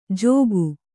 ♪ jōgu